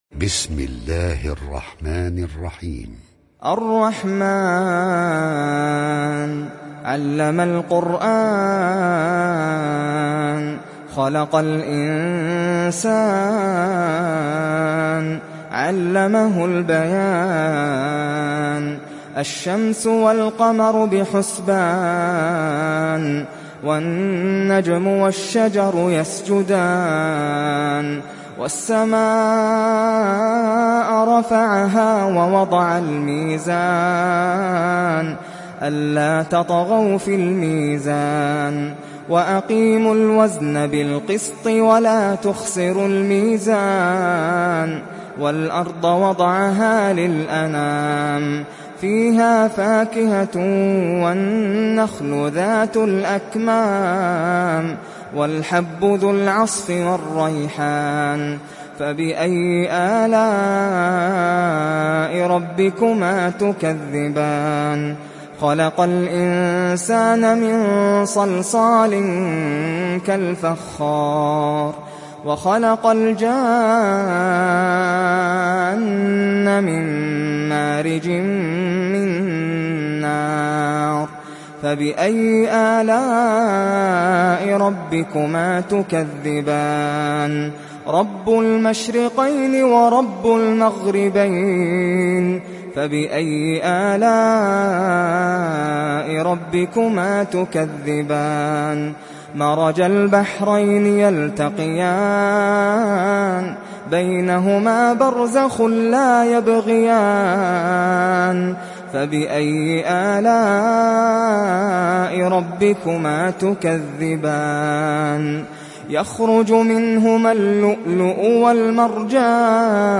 Rahman Suresi İndir mp3 Nasser Al Qatami Riwayat Hafs an Asim, Kurani indirin ve mp3 tam doğrudan bağlantılar dinle